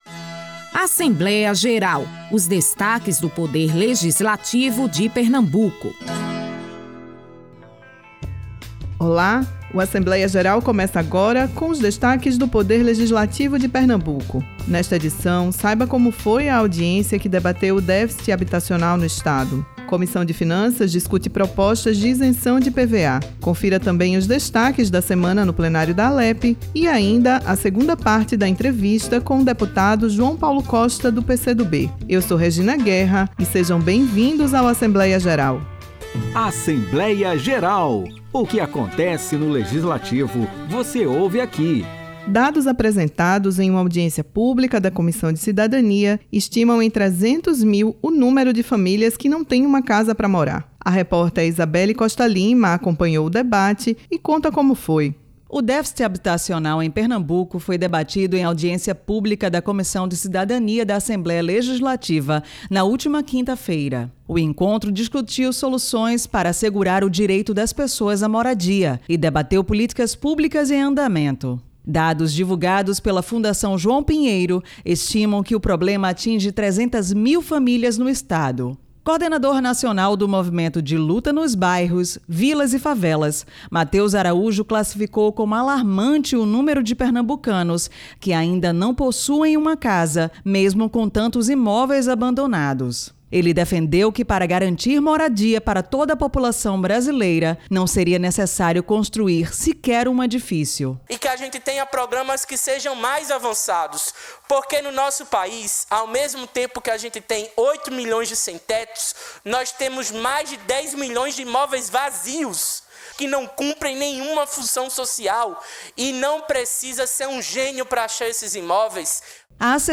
Na última edição do Assembleia Geral, o programa mostra a audiência que debateu o déficit habitacional no Estado. Além disso, o episódio fala sobre as propostas de isenção de IPVA em discussão na Alepe. Você também pode conferir os destaques do Plenário da Alepe na semana e a segunda parte da entrevista com o deputado João Paulo Costa.
O programa Assembleia Geral é uma produção semanal da Rádio Alepe, com os destaques do Legislativo pernambucano.